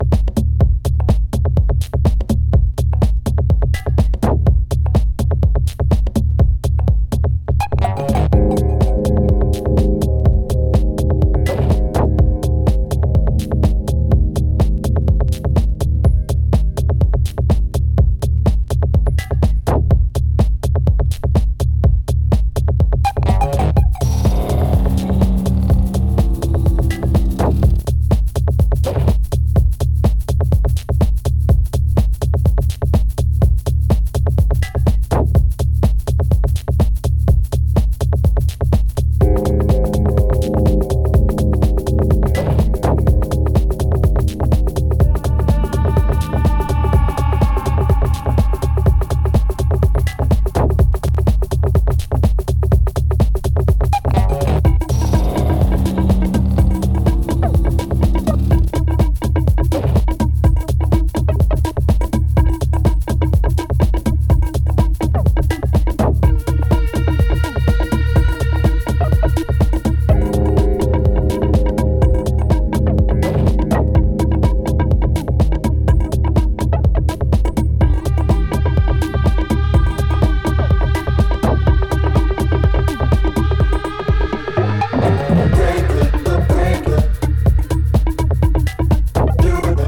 妖しい声ネタやテクノ的SEを挟みながらミニマルに展開するダビー・テックハウスを両面に収録。
DEEP HOUSE / EARLY HOUSE